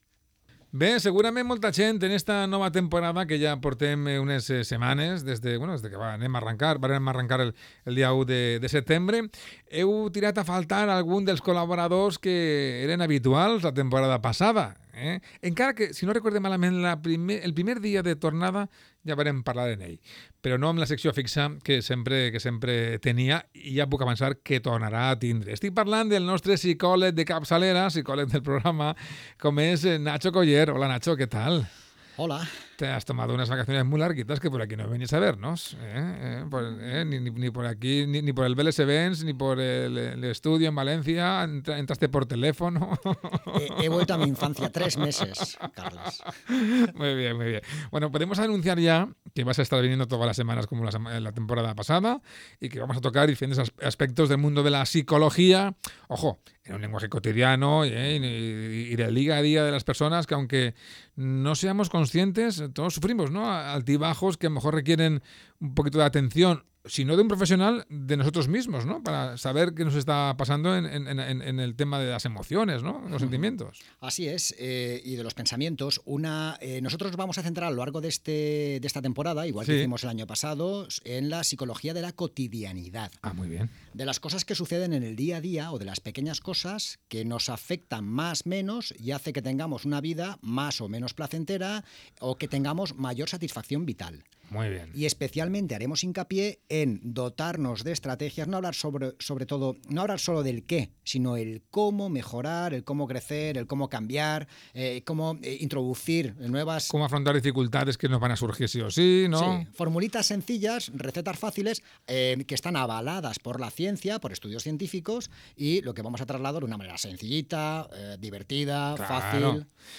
Hablando de psicología en las ondas. Entrevista